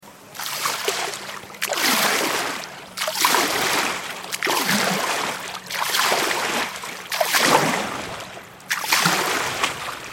دانلود آهنگ دریا 6 از افکت صوتی طبیعت و محیط
دانلود صدای دریا 6 از ساعد نیوز با لینک مستقیم و کیفیت بالا
جلوه های صوتی